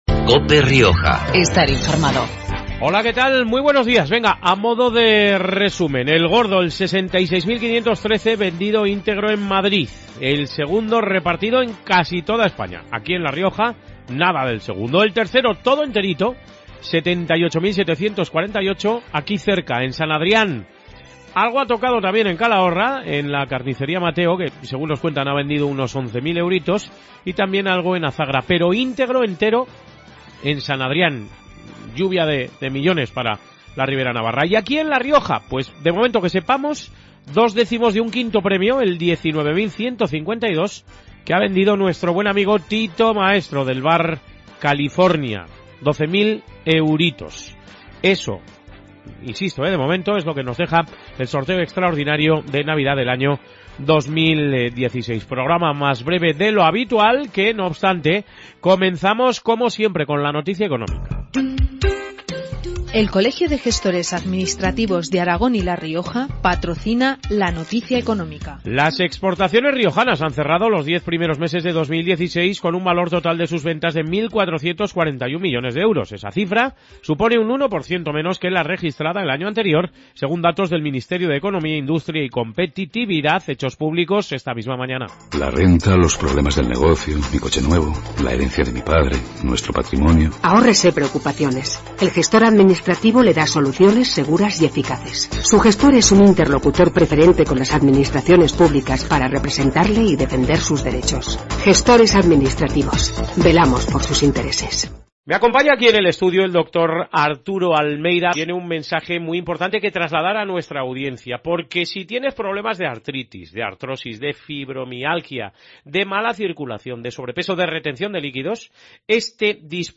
Programa regional de actualidad, entrevistas y entretenimiento. Hoy, emisión más breve de lo habitual debido al Sorteo Extraordinario de Navidad.